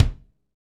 Index of /90_sSampleCDs/Northstar - Drumscapes Roland/DRM_Funk/KIK_Funk Kicks x
KIK FNK K0BR.wav